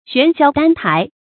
璇霄丹臺 注音： ㄒㄨㄢˊ ㄒㄧㄠ ㄉㄢ ㄊㄞˊ 讀音讀法： 意思解釋： 見「璇霄丹闕」。